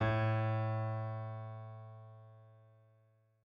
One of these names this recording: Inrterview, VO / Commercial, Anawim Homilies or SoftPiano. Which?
SoftPiano